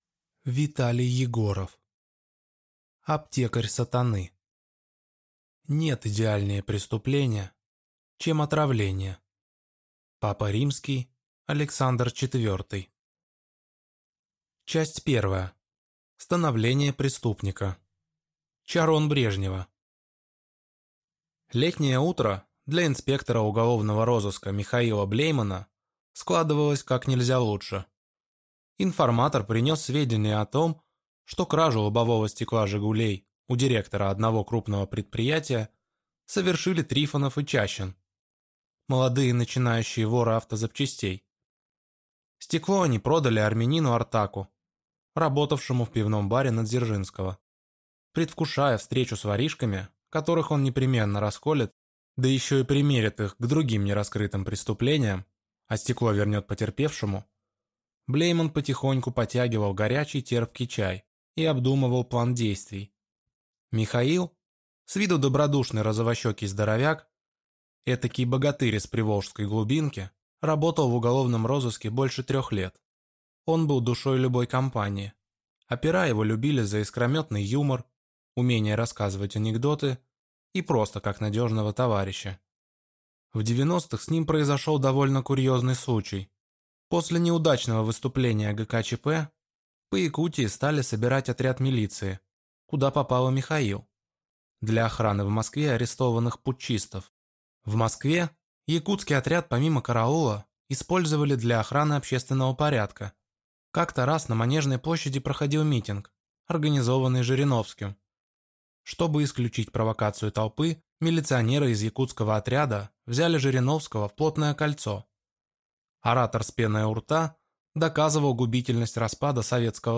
Аудиокнига Аптекарь сатаны | Библиотека аудиокниг